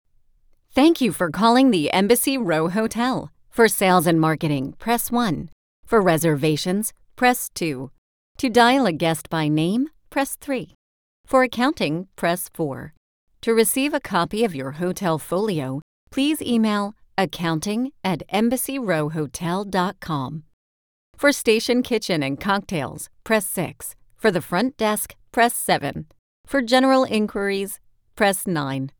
That weird distorted voice
You simply type in the greeting that you want, and the robot voice records it for you…in a weird distorted voice.
Maybe some people like talking with Robots.